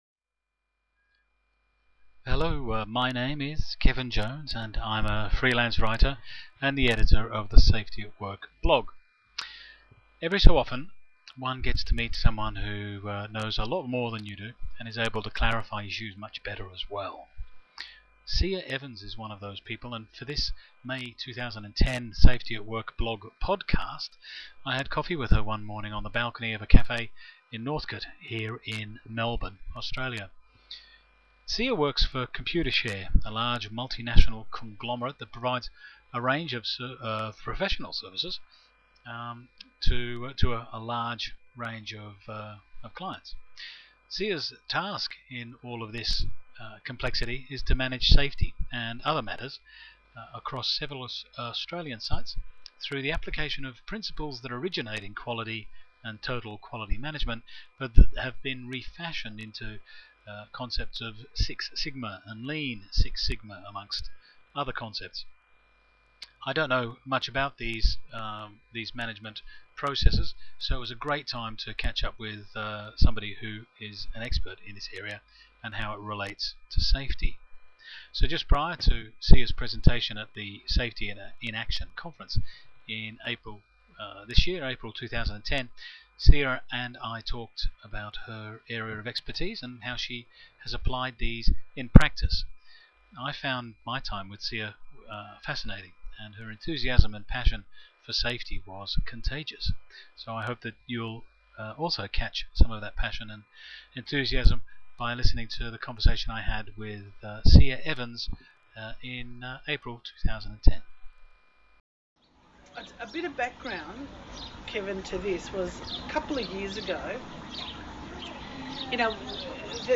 The interview has been edited into a podcast that can be accessed below.